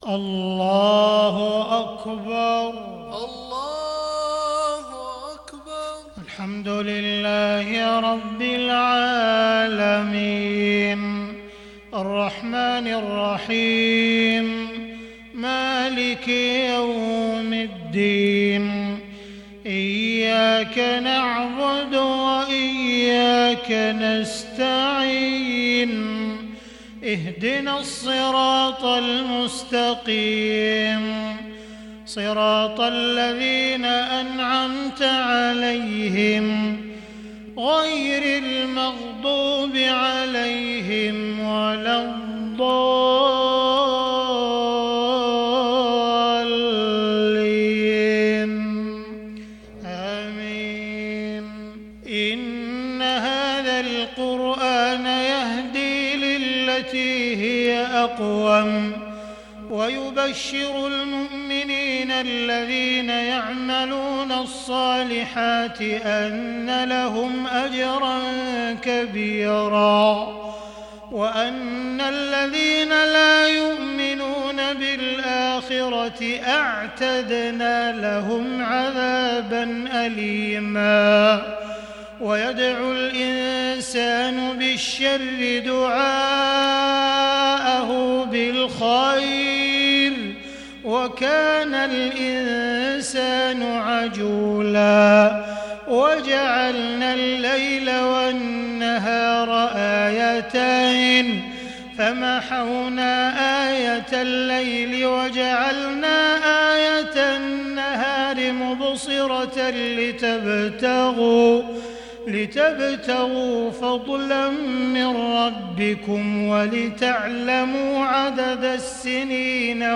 صلاة الفجر للشيخ عبدالرحمن السديس 19 شعبان 1441 هـ
تِلَاوَات الْحَرَمَيْن .